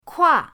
kua4.mp3